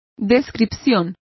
Complete with pronunciation of the translation of portrayal.